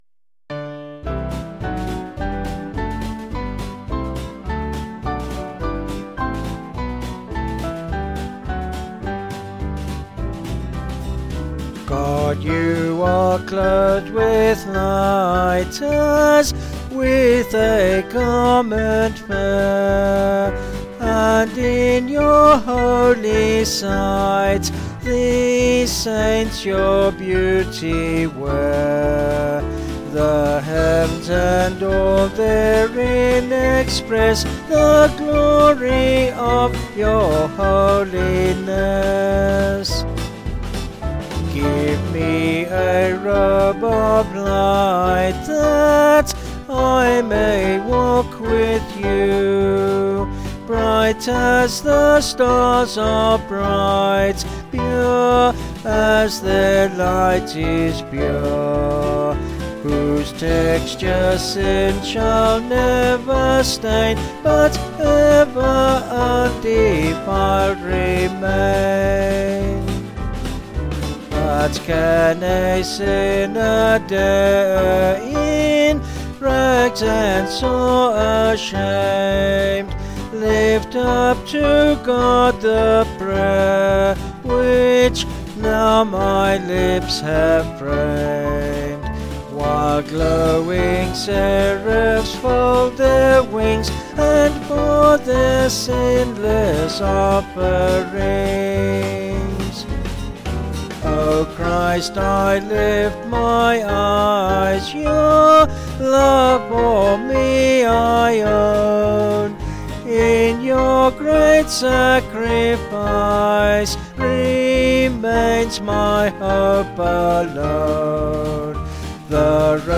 Vocals and Band   263.1kb Sung Lyrics